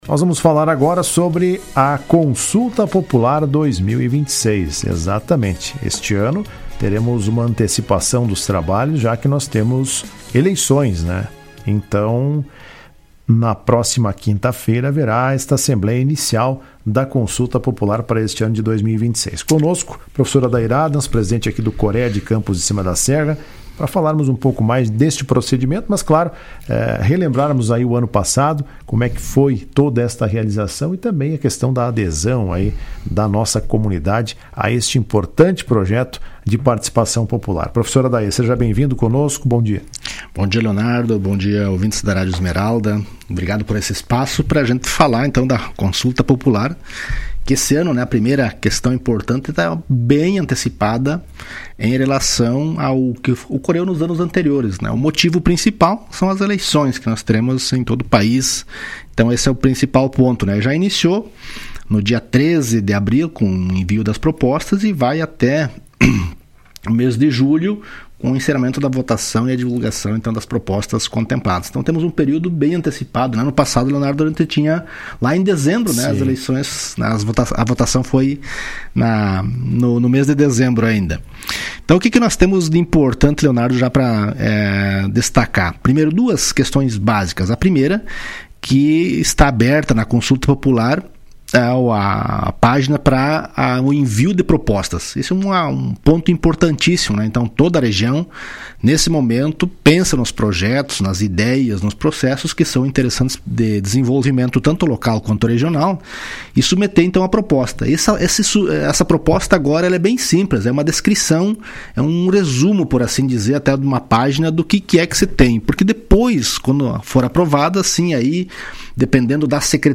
Em entrevista ao programa Comando Geral nesta segunda-feira